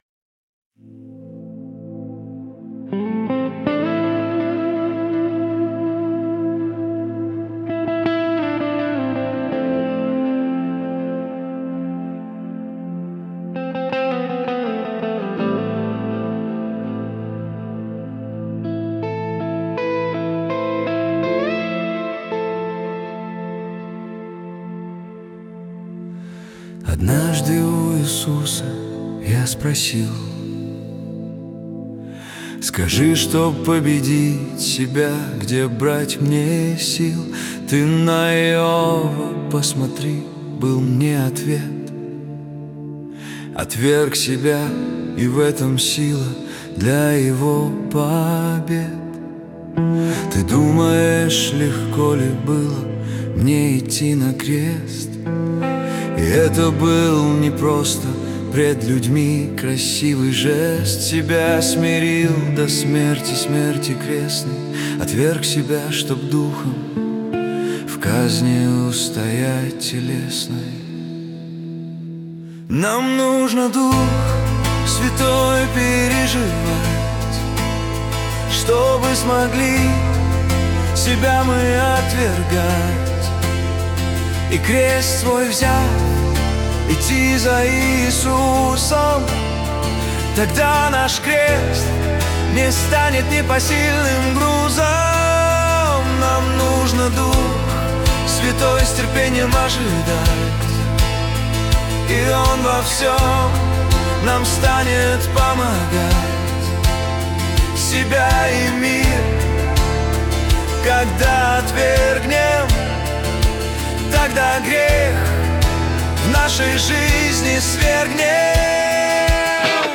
песня ai
235 просмотров 718 прослушиваний 86 скачиваний BPM: 82